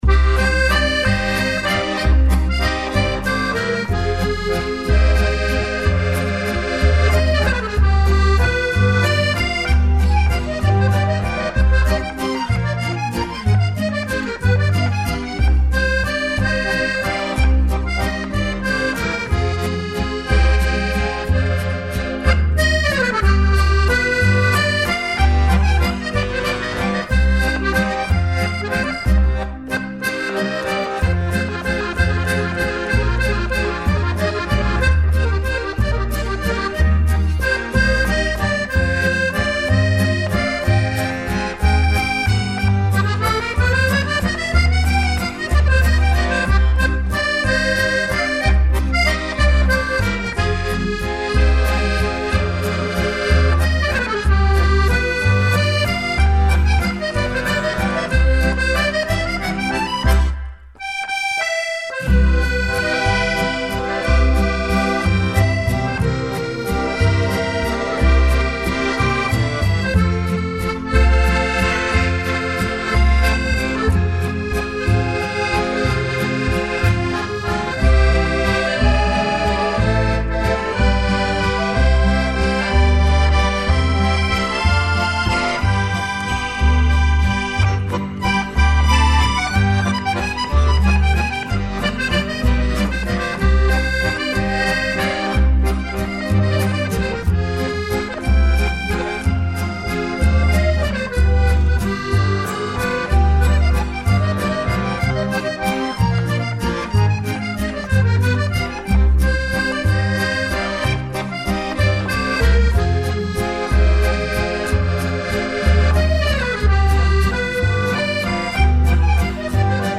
Akoestisch trio
mobiele muziek Trio